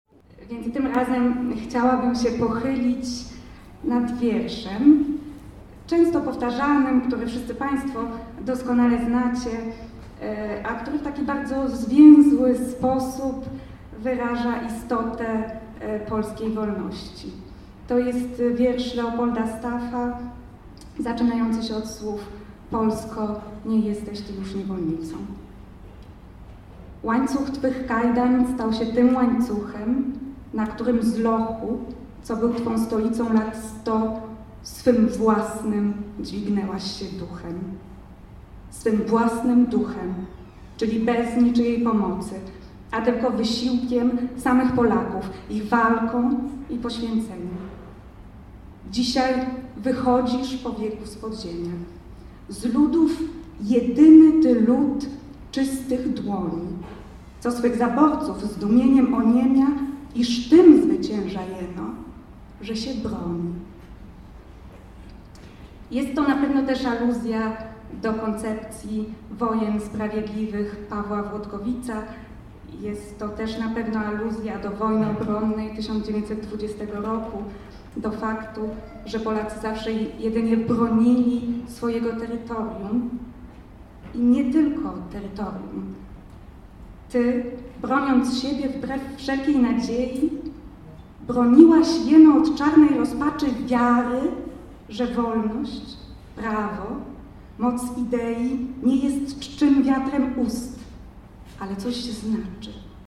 Wicekonsul Dorota Preda - fragmenty recytacji